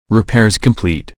repaircomplete.ogg